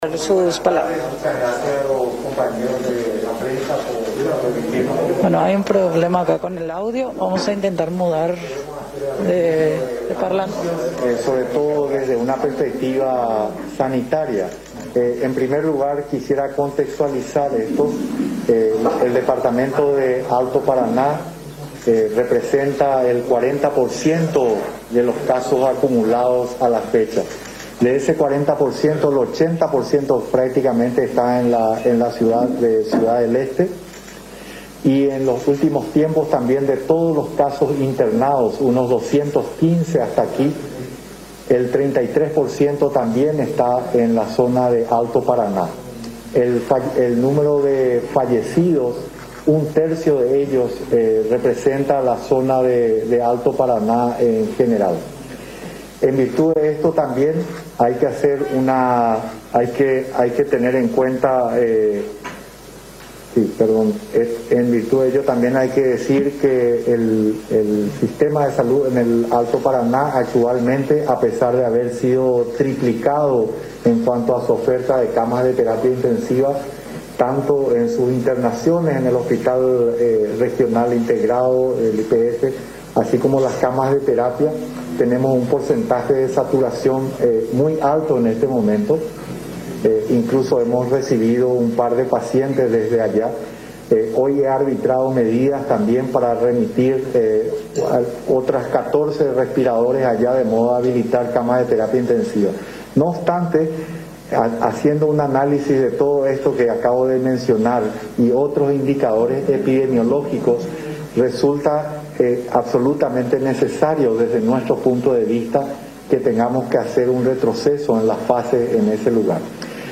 “Estamos planteando instalar una fase 0.5 en Alto Paraná. Podemos manejarnos con ciertas libertades de la fase 1”, mencionó el Ministro Julio Mazzoleni en conferencia prensa.
20-COMFERENCIA-DE-JULIO-MAZZOLENI-Y-LIZ-CRAMER.mp3